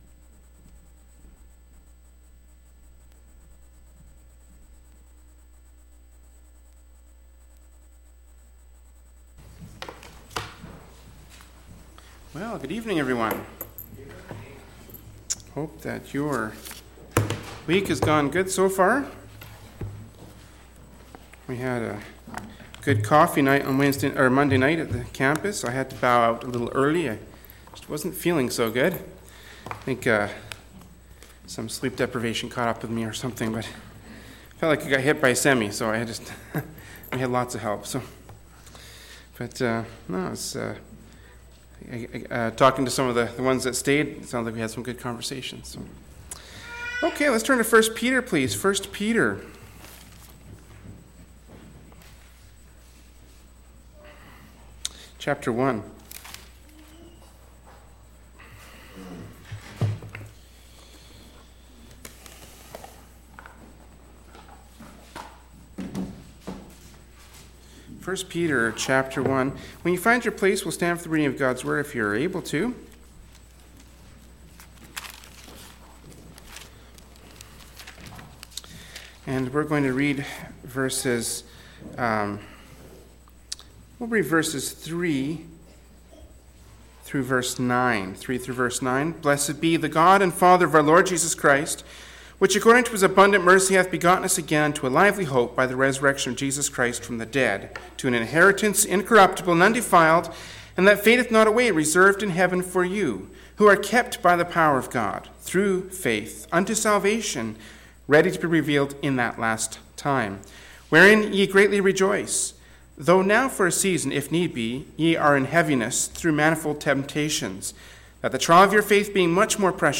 “1st Peter 1:3-9” from Wednesday Evening Service by Berean Baptist Church.